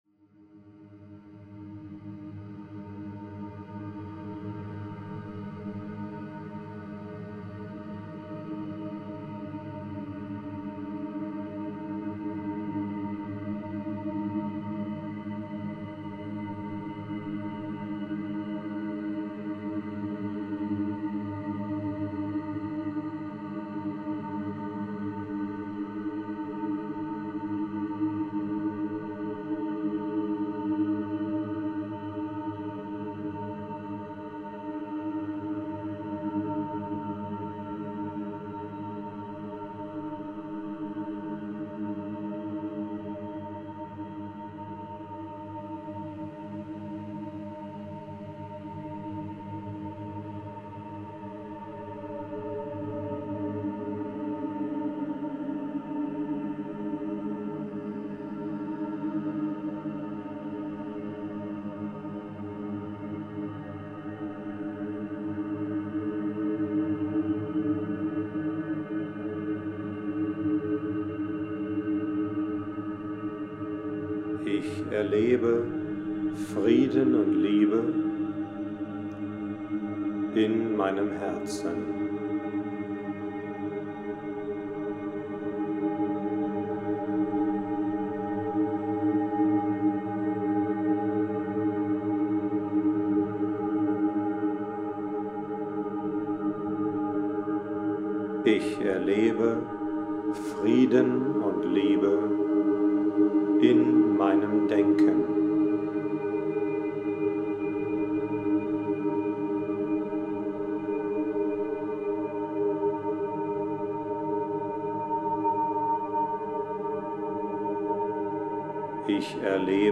frieden-und-liebe-meditation11min.mp3